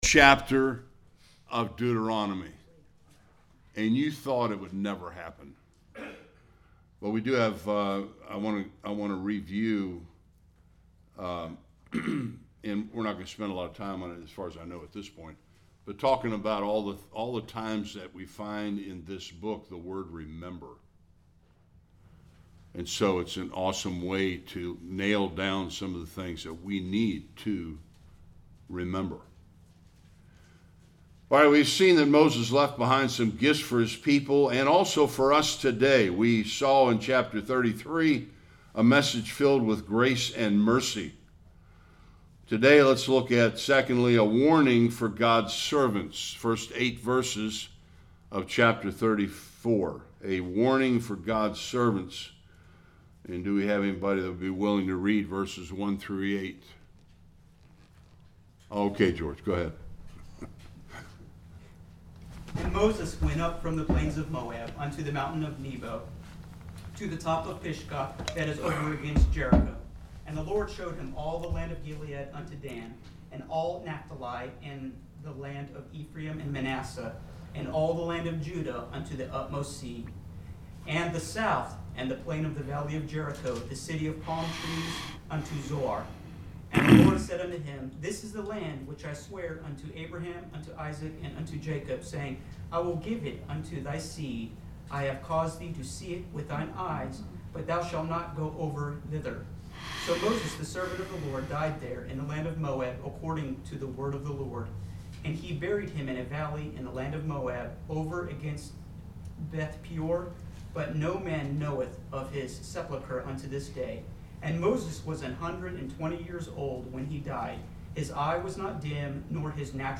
1-12 Service Type: Sunday School The book concludes with Moses’s final words recorded after his death.